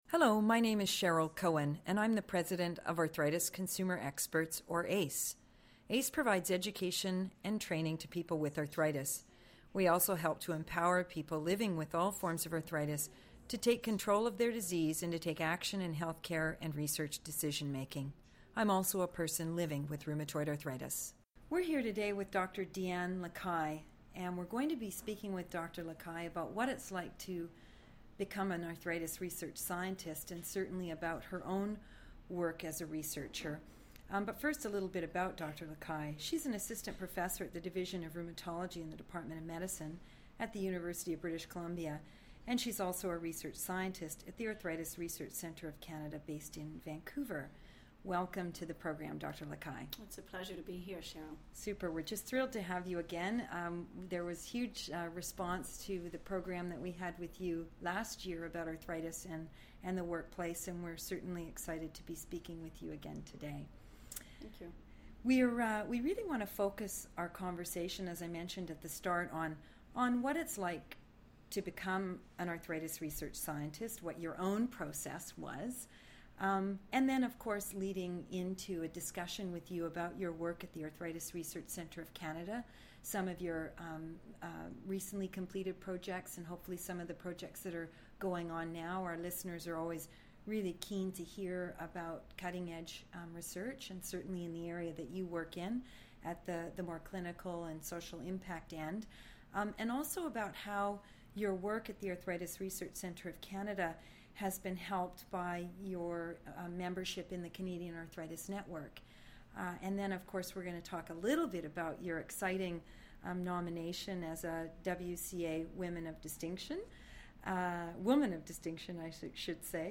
A Conversation With an Arthritis Research Scientist « JointHealth™ podcasts | changing arthritis